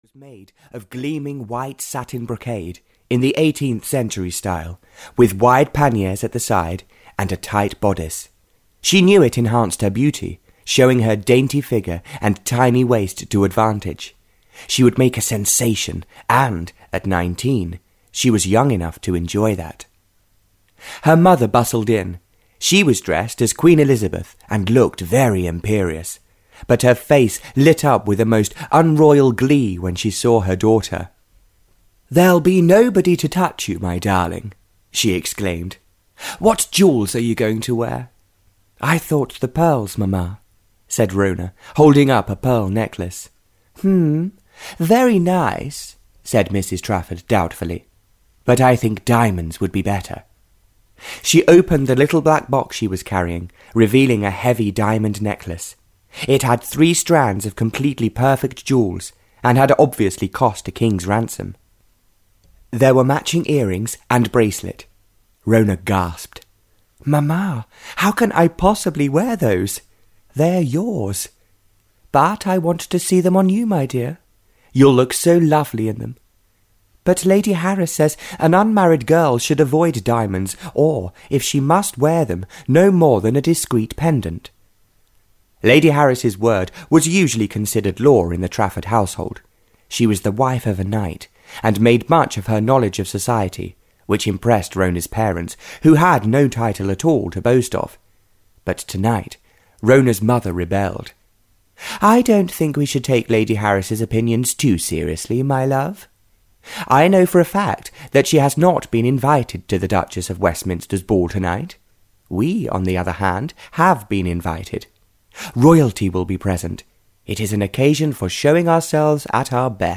Love Became Theirs (Barbara Cartland’s Pink Collection 9) (EN) audiokniha
Ukázka z knihy